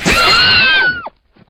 PixelPerfectionCE/assets/minecraft/sounds/mob/horse/hit4.ogg at mc116